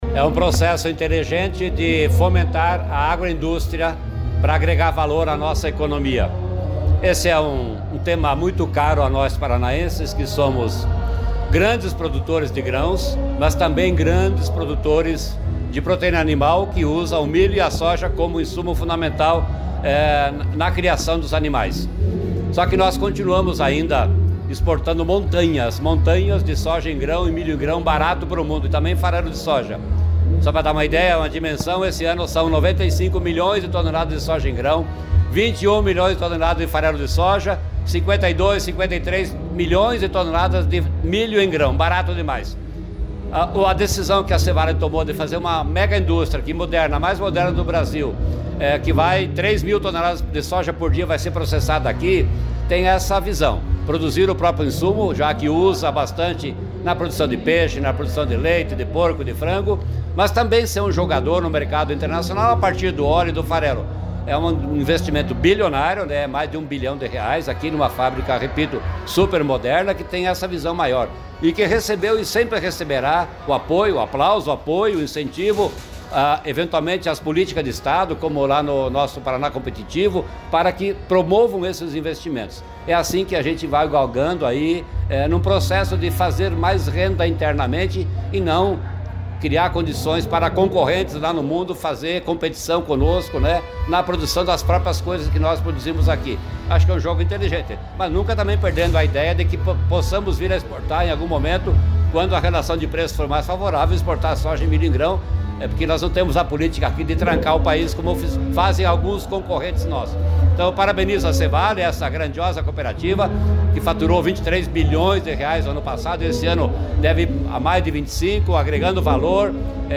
Sonora do secretário Estadual da Agricultura e do Abastecimento, Norberto Ortigara, sobre a inauguração da esmagadora da C.Vale, em Palotina